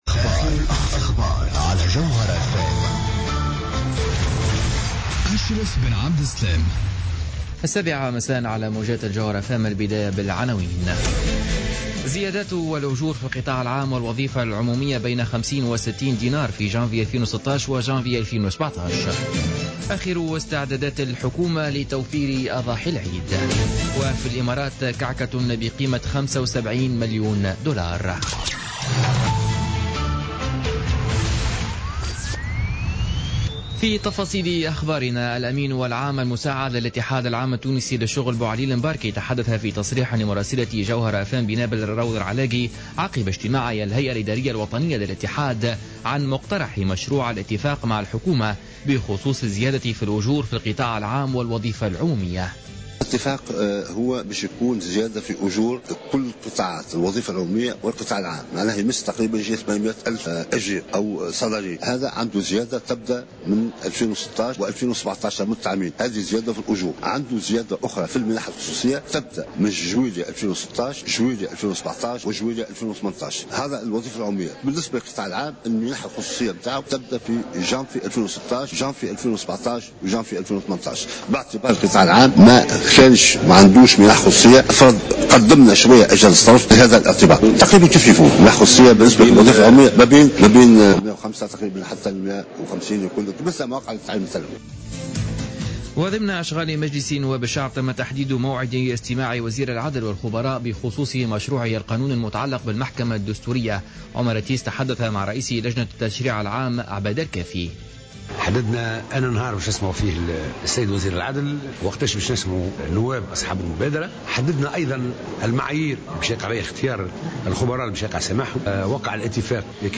نشرة أخبار السابعة مساء ليوم الأربعاء 16 سبتمبر 2015